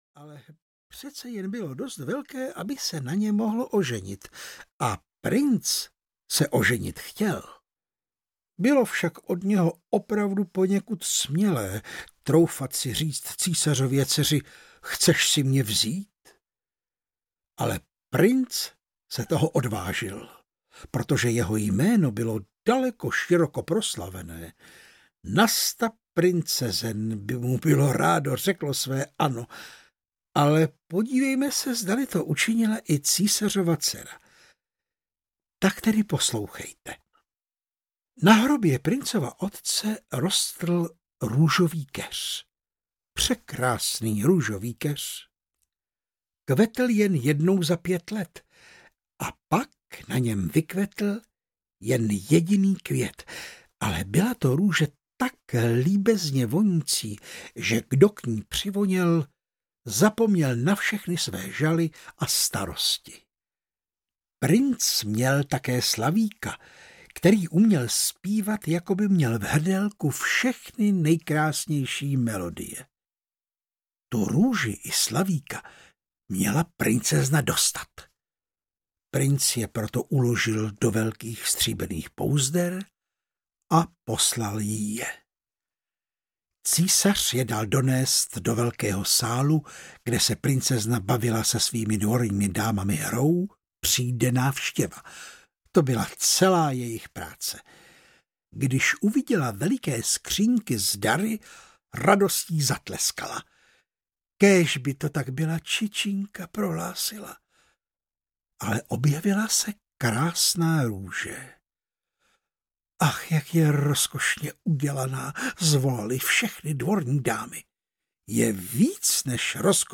Pasáček vepřů audiokniha
Ukázka z knihy